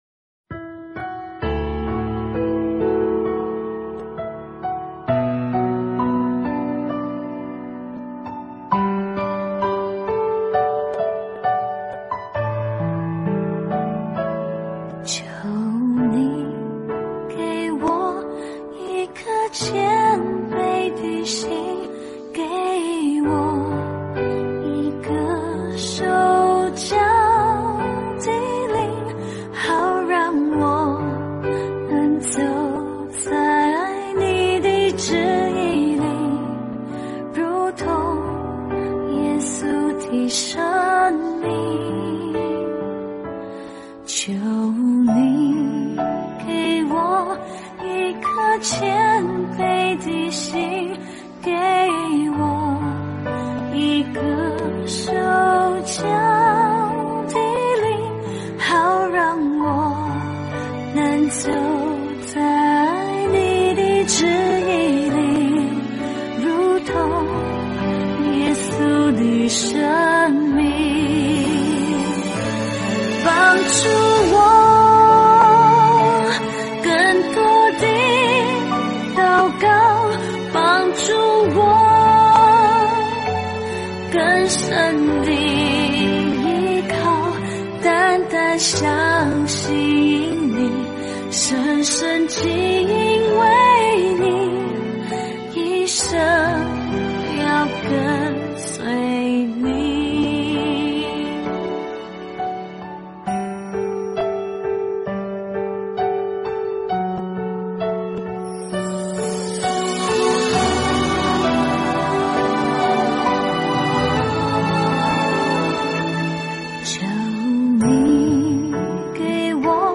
赞美诗｜一颗谦卑的心